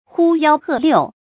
呼幺喝六 注音： ㄏㄨ ㄧㄠ ㄏㄜˋ ㄌㄧㄨˋ 讀音讀法： 意思解釋： 呼、喝：叫喊；幺：一；骰子的六面分別標有一至六的點數。